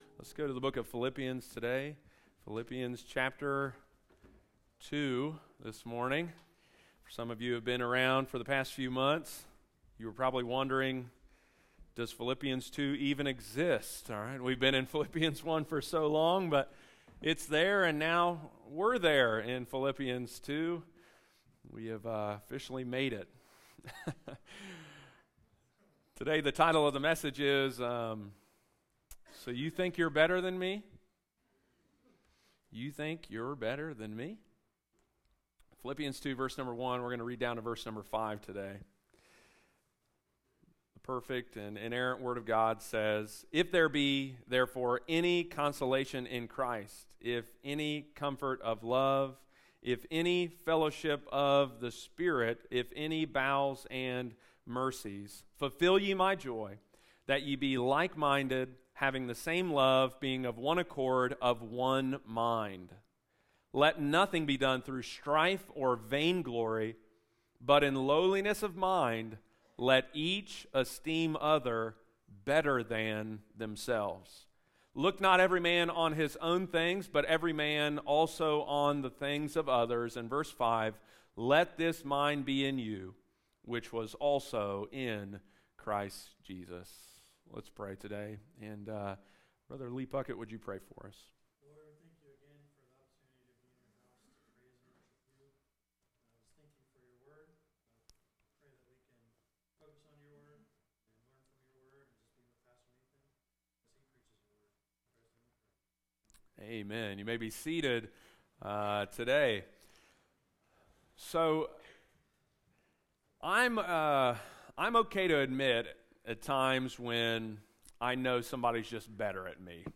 Sunday morning, August 27, 2023.